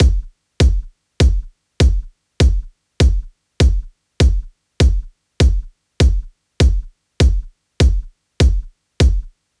Unison Funk - 1 - 100bpm - Kick.wav